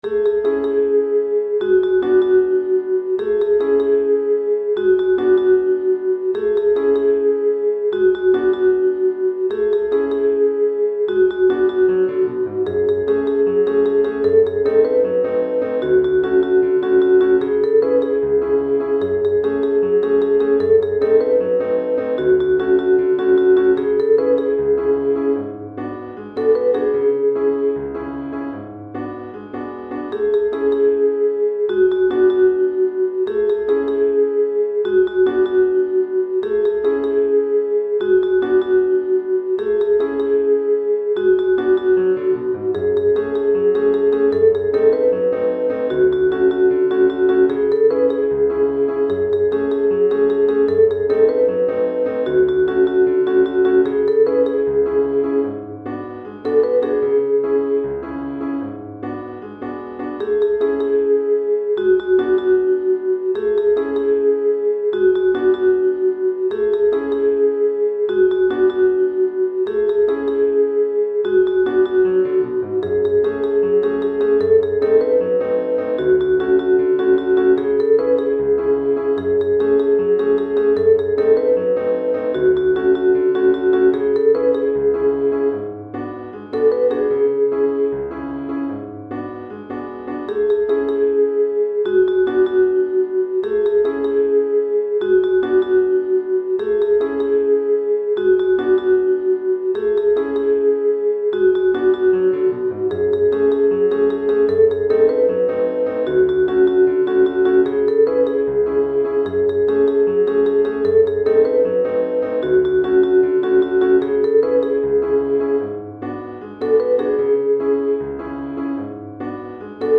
Chorale d'Enfants et Piano ou Guitare